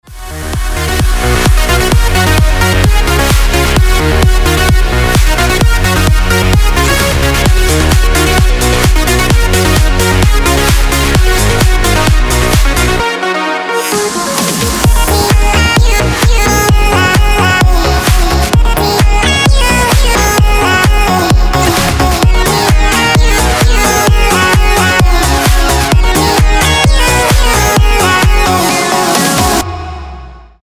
• Качество: 320, Stereo
Electronic
EDM
энергичные
Стиль: electro house